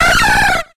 Grito de Skiploom.ogg
Grito_de_Skiploom.ogg.mp3